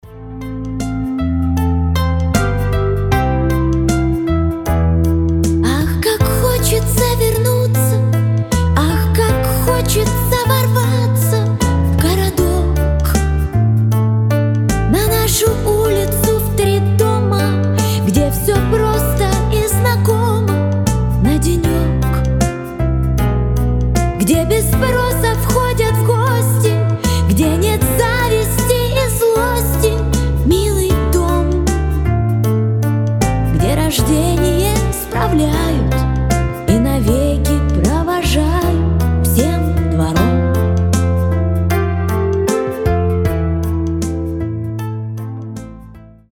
грустные Ностальгия Душевные